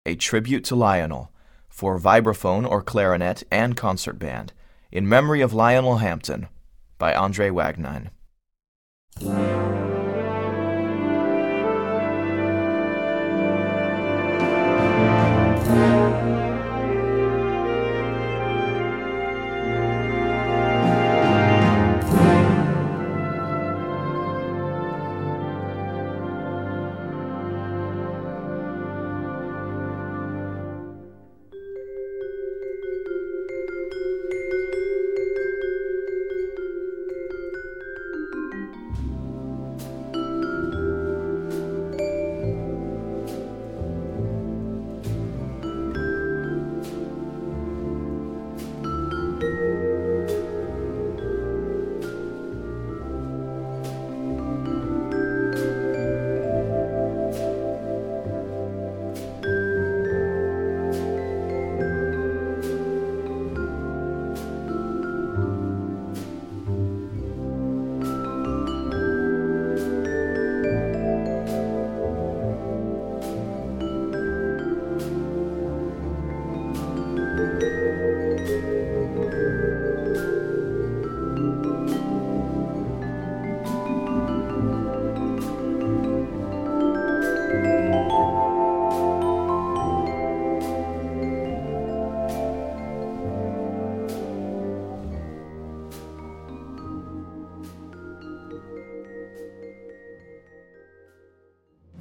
Voicing: Vibes Solo w/ Band